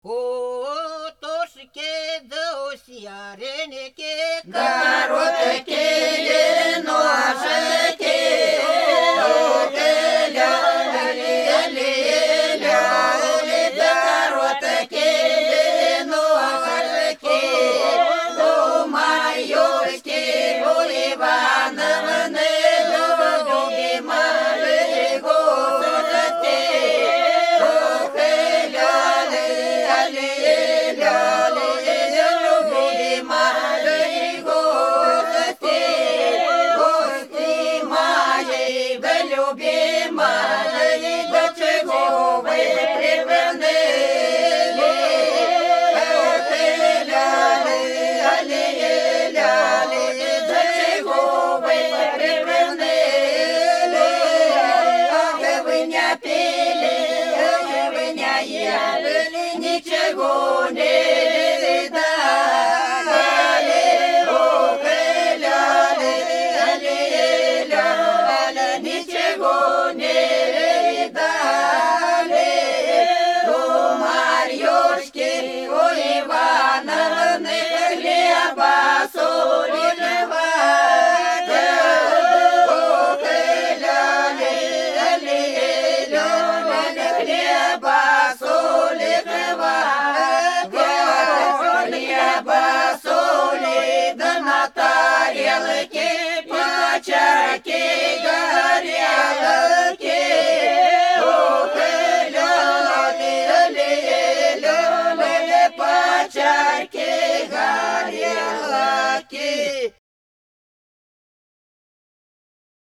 Пролетели все наши года У утушки, у серенькой короткие ножки – свадебная, величальная хозяевам (Фольклорный ансамбль села Подсереднее Белгородской области)